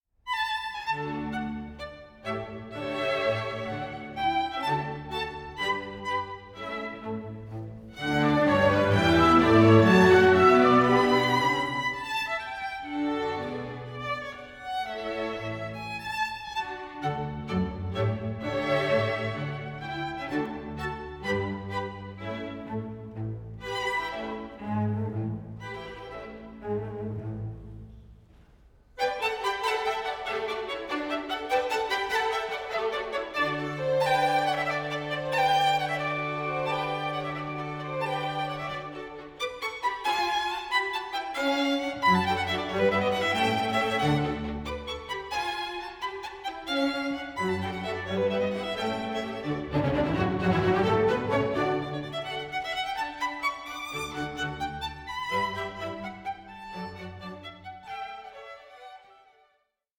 Andante cantabile 6:36